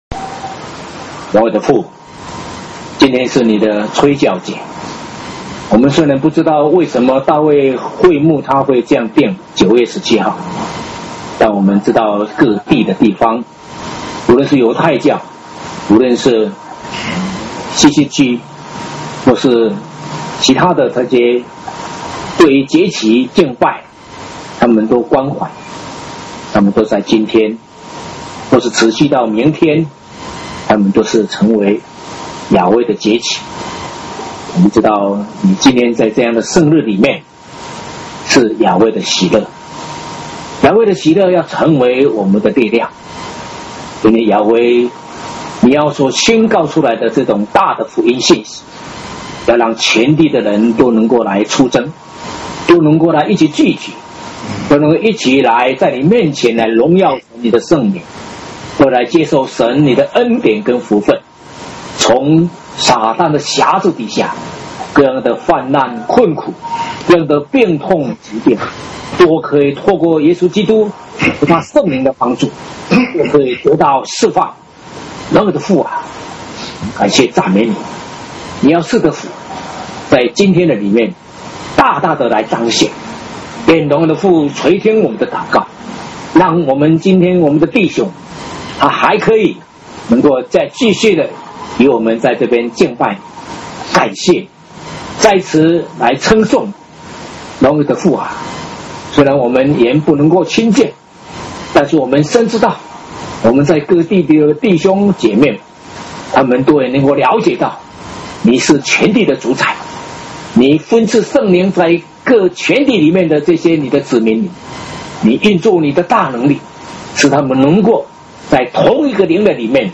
2013年吹角節聖會-禱告